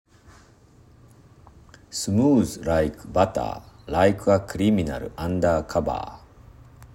通常カタカナ スムーズ　ライク　バター　ライク　ア　クリミナル　アンダーカバー